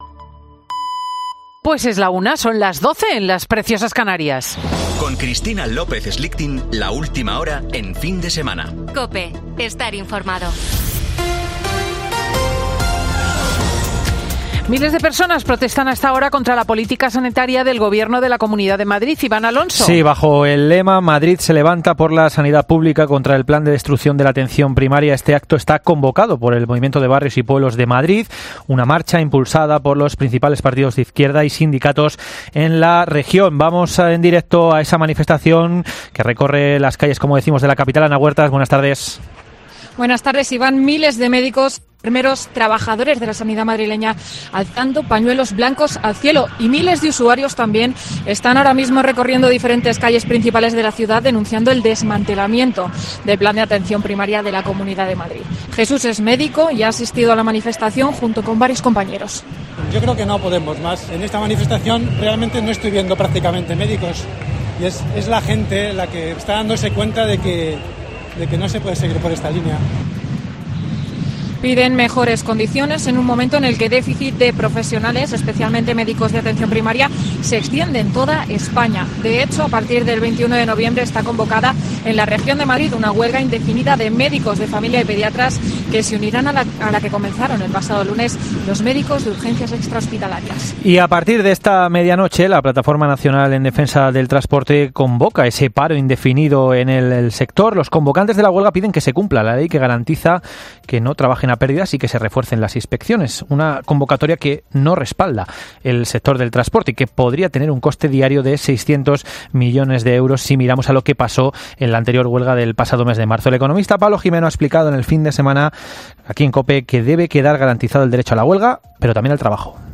Boletín de noticias de COPE del 13 de noviembre de 2022 a las 13.00 horas